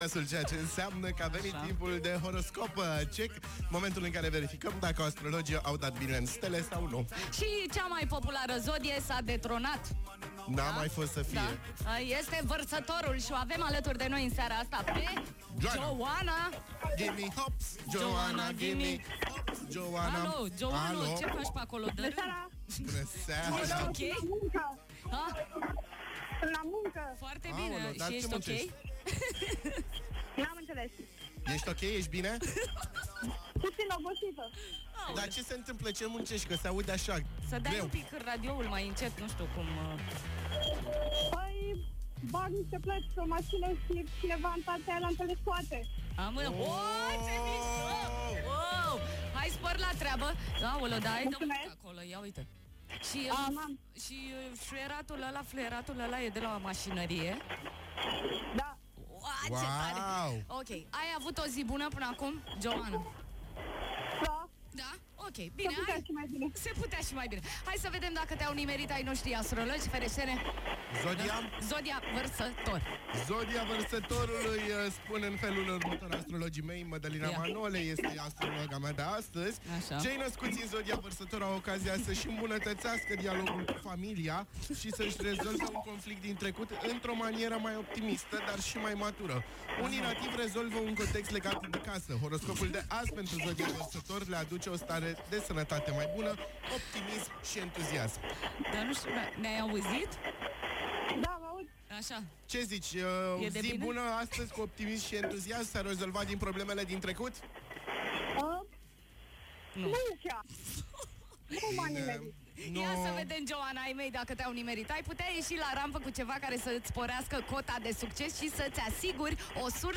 Verifică aici ce s-a întâmplat în direct(AUDIO)